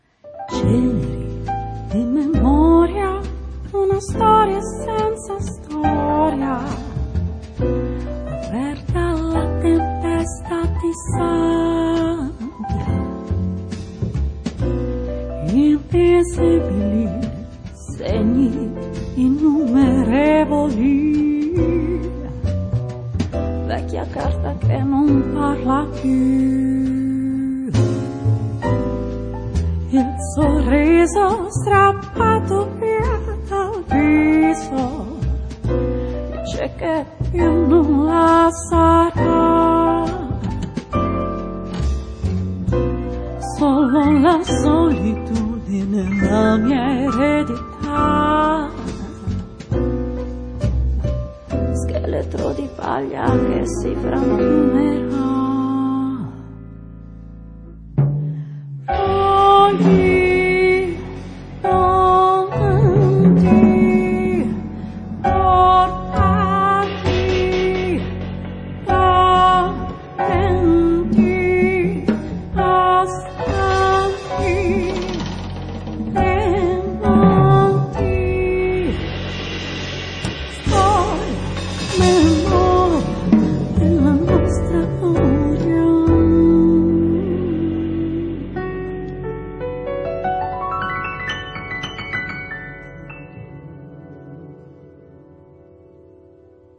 晚安曲依舊是週末的爵士樂。
獨特的嗓音，相當迷人。